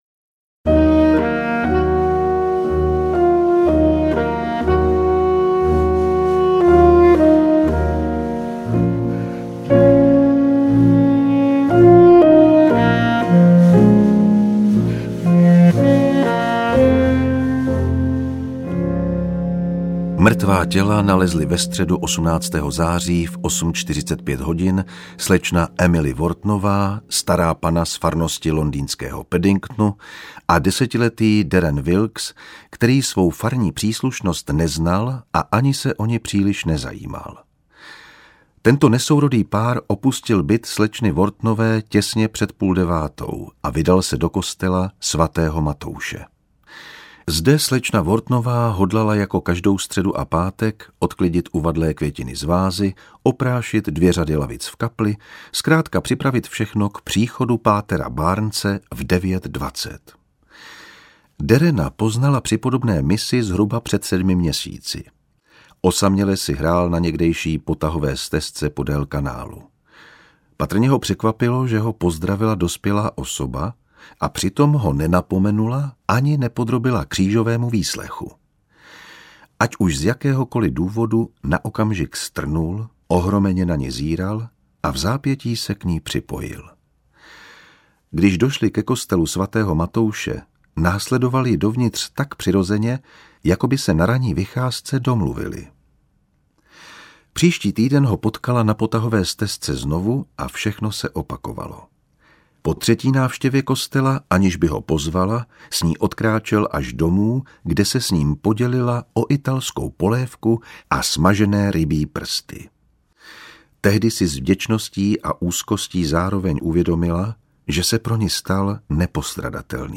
Interpret:  Jan Vondráček
Postavě komisaře Adama Dalglieshe, který je esencí britského gentlemanství, vdechla docela věčný život, o čemž svědčí i rozhlasové nastudování románu Pachuť smrti, jež vyšel před pětatřiceti lety, ale stále má i díky charismatické...